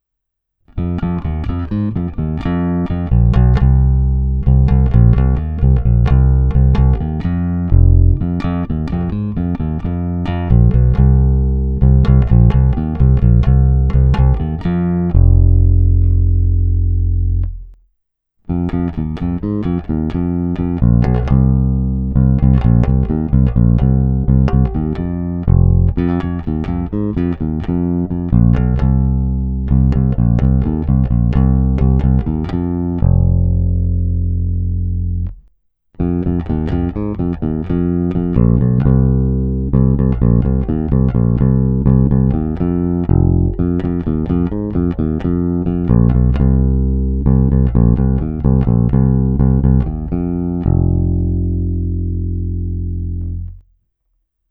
Parádní tučný, pevný, zvonivý zvuk s těmi správnými středy, které tmelí kapelní zvuk a zároveň dávají base vyniknout.